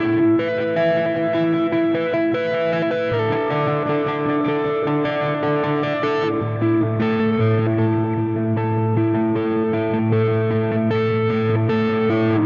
Spaced Out Knoll Electric Guitar 03a.wav